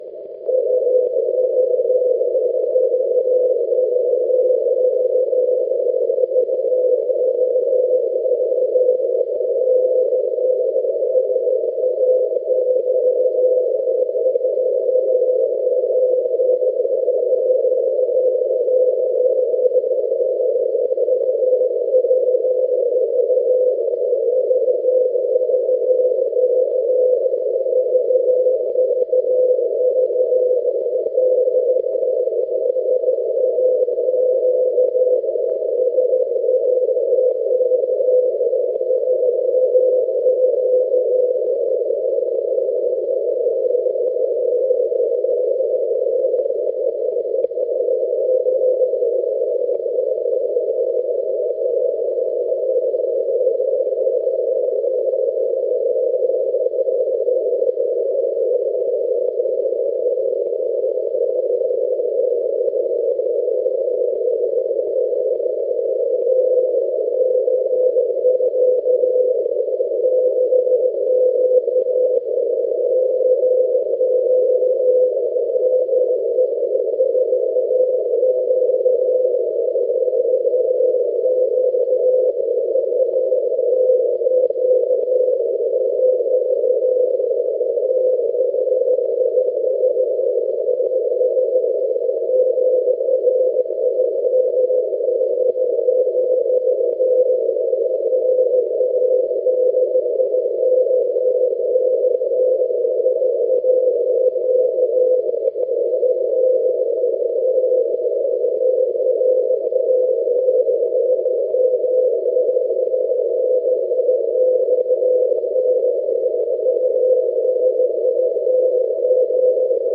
This is Grimeton Radio / SAQ transmitting their annual Christmas Eve message on 17.2 kHz using the 200 kW Alexanderson alternator. The signal is weak but mostly readable to the trained weak signal ear.
Then there is a brief pause fallowed by the message. You won't often hear hand sent Morse code from commercial stations these days! The message also contains punctuation you will very rarely hear on the airwaves in Morse code - the colon is used twice.